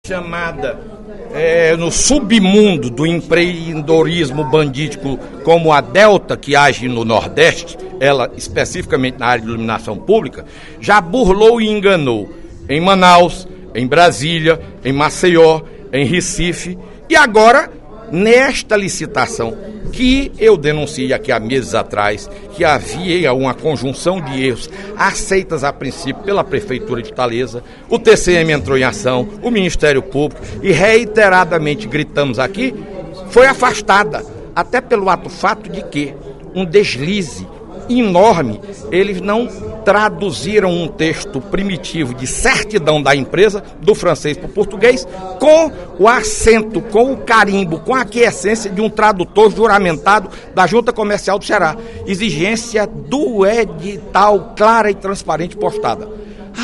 Em pronunciamento durante o primeiro expediente da sessão plenária desta quinta-feira (14/06), o deputado Fernando Hugo (PSDB) voltou a contestar documento apresentado pela empresa Citéluz em processo licitatório  da Autarquia Municipal de Trânsito, Serviços Públicos e Cidadania de Fortaleza (AMC).